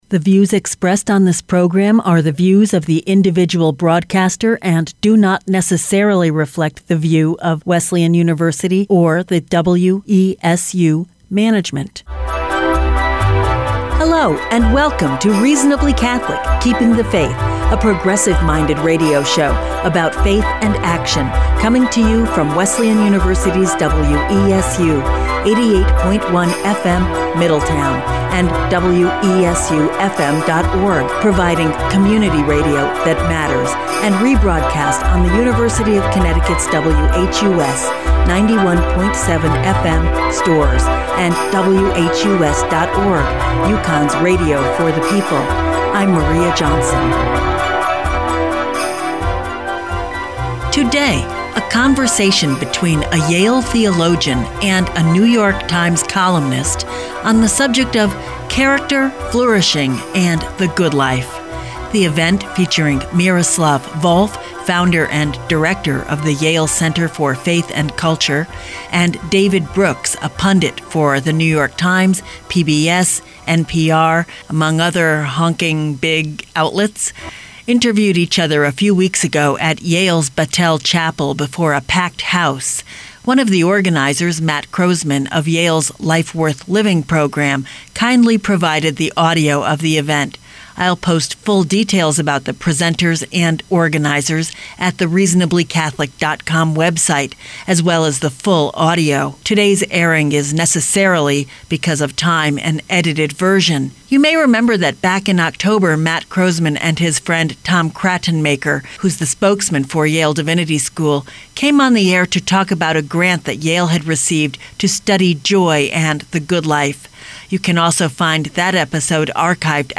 The event was sponsored by Yale’s Life Worth Living Program.